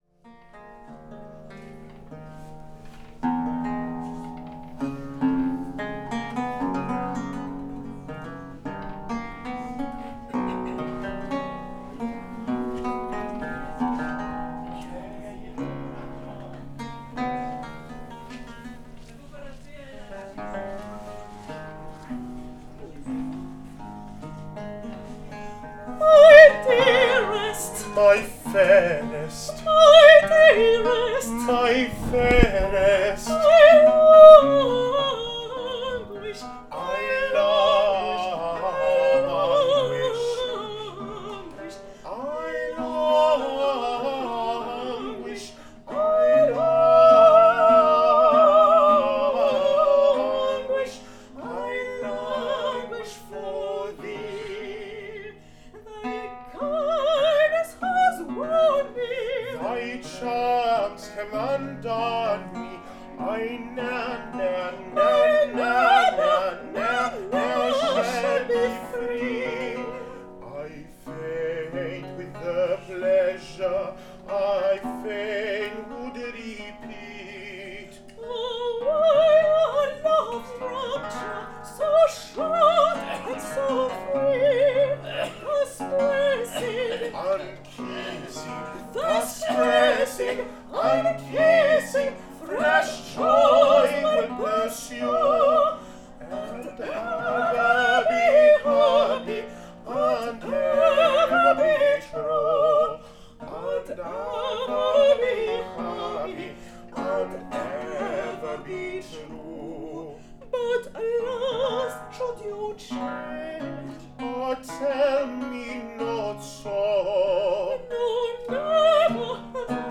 soprano
chanteur et claveciniste
théorbe
La matinée est consacrée à des concerts aux chevets pour les résidents de l’EHPAD.